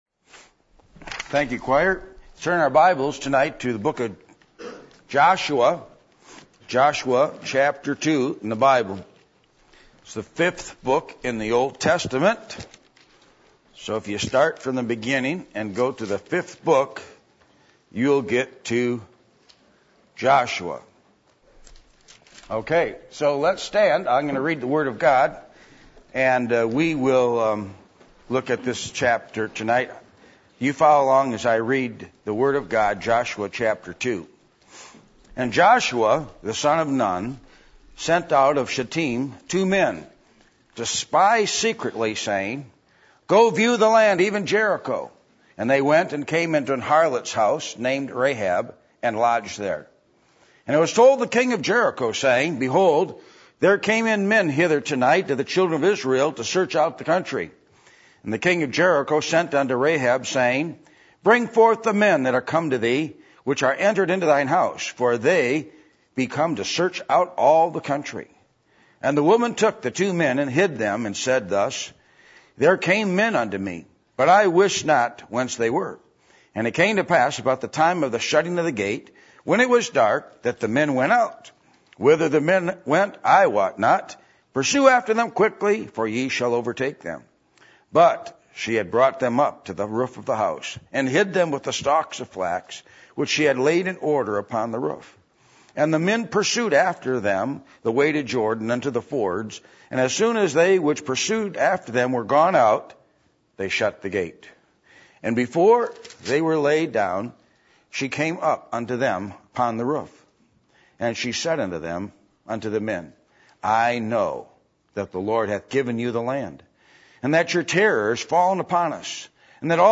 Passage: Joshua 2:1-24 Service Type: Sunday Evening %todo_render% « The Message Of Jesus’ Baptism A Picture Of Worship